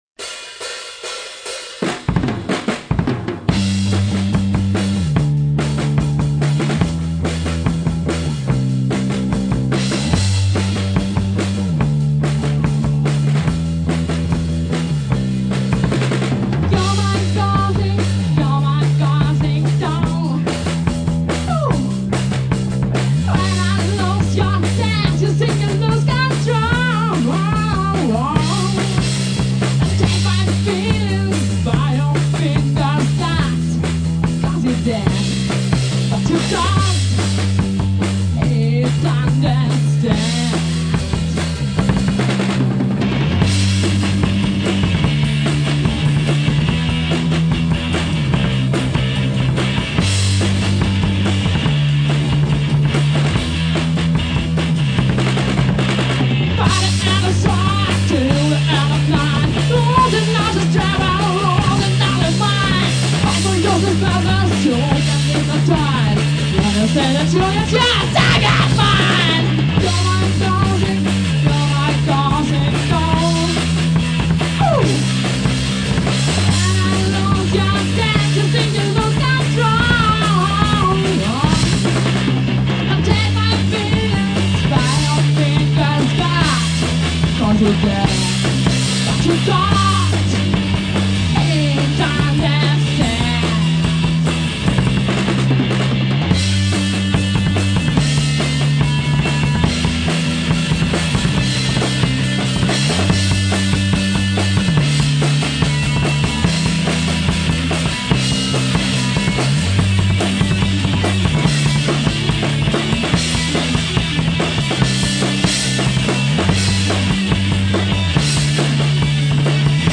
скачать (punk Rock)